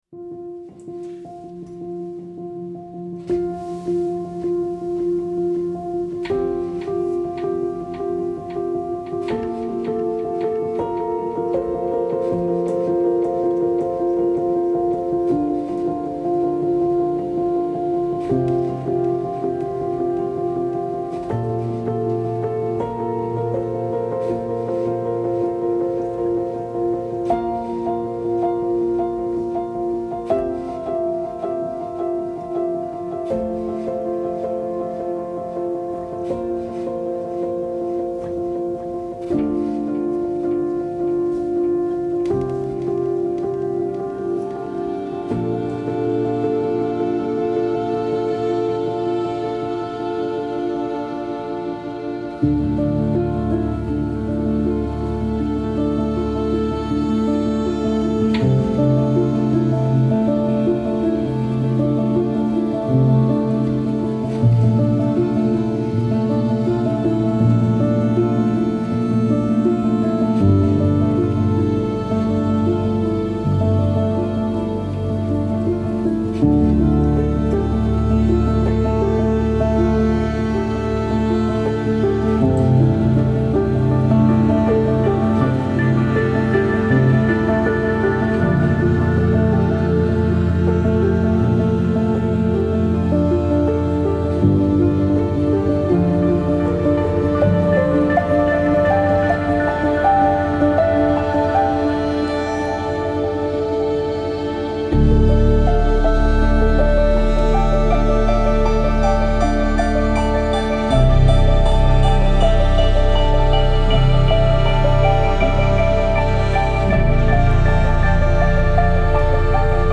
Classical Crossover
الهام‌بخش , پیانو , غم‌انگیز